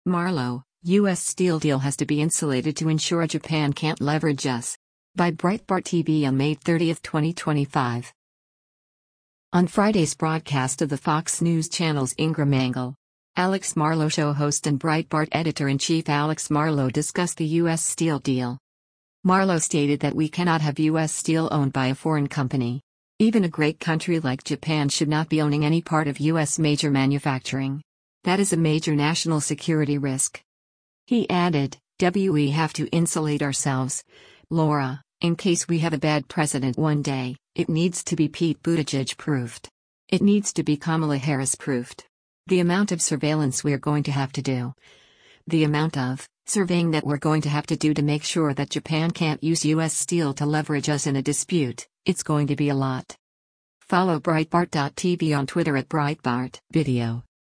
On Friday’s broadcast of the Fox News Channel’s “Ingraham Angle,” “Alex Marlow Show” host and Breitbart Editor-in-Chief Alex Marlow discussed the U.S. Steel deal.